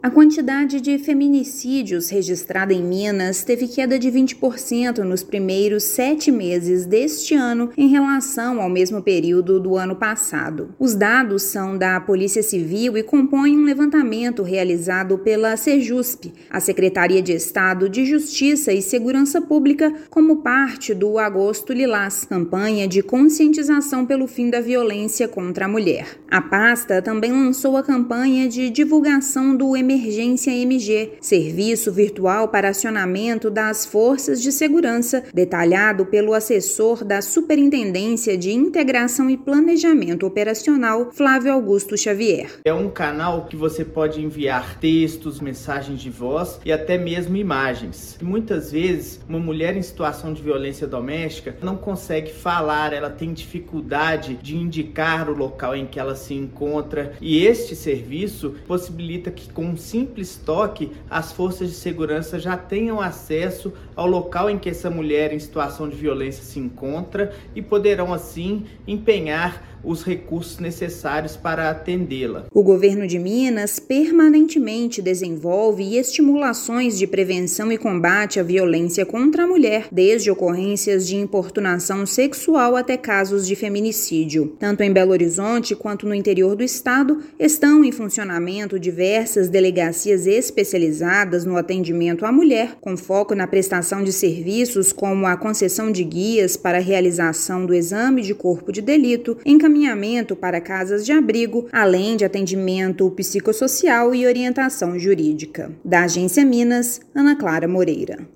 Mulheres vítimas de violência doméstica podem chamar as polícias apenas teclando, além de enviar fotos, localização ou fazer chamada de vídeo por meio do Emergência MG. Ouça matéria de rádio.